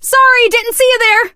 max_kill_vo_01.ogg